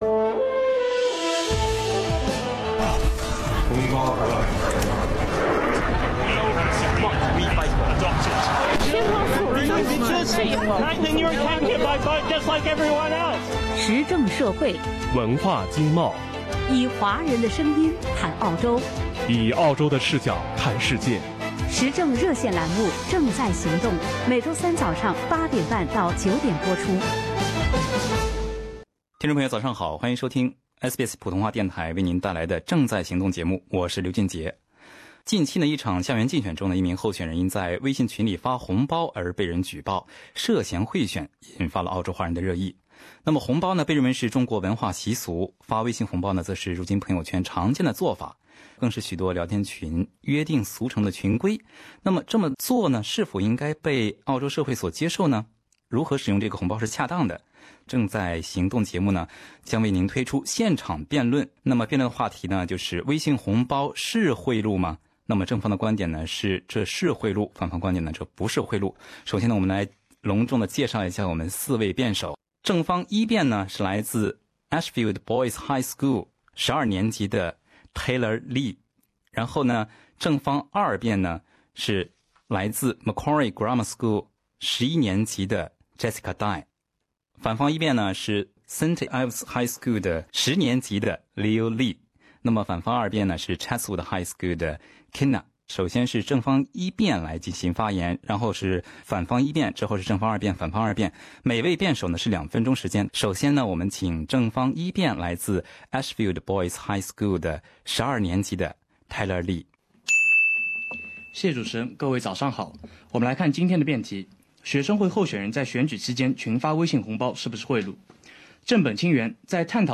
今天早上8：30分，《正在行动》栏目 推出现场辩论，四位中学生将来到直播现场，就此话题进行辩论交锋。